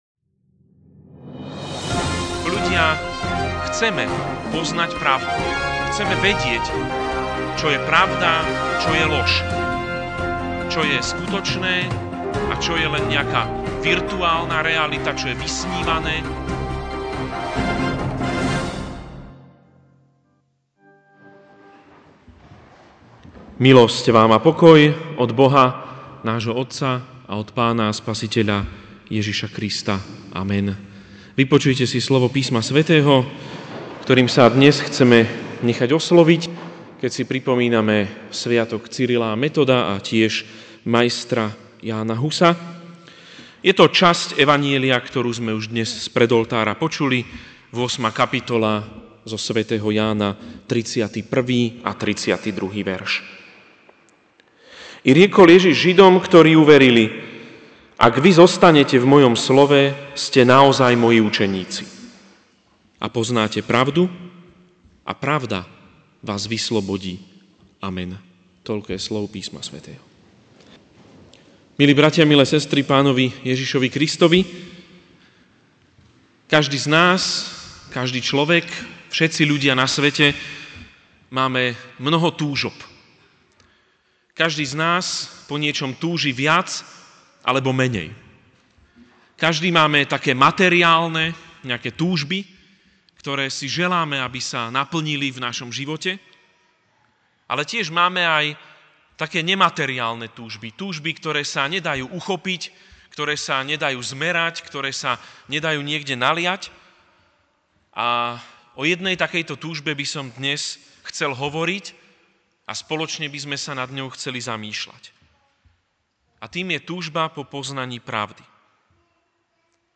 Ranná kázeň: Cyril a Metod: Pravda (Ján 8, 31-32)